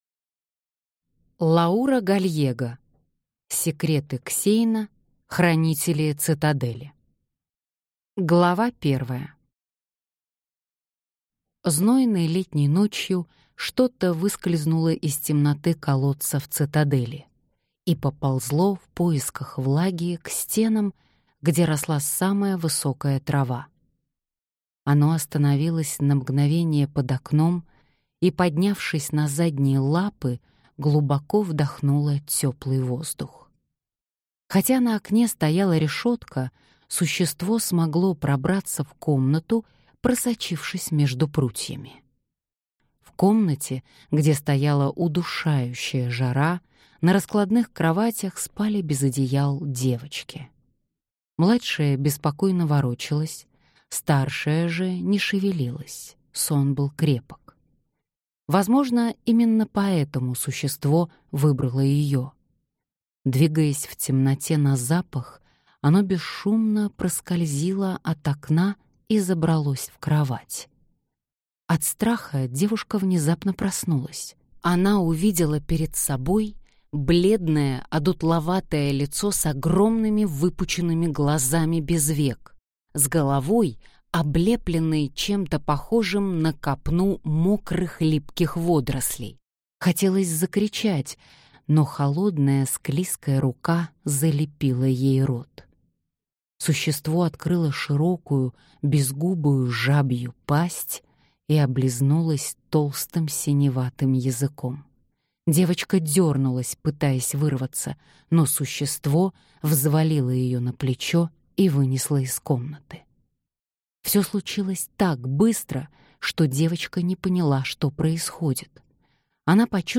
Аудиокнига Секреты Ксейна | Библиотека аудиокниг
Прослушать и бесплатно скачать фрагмент аудиокниги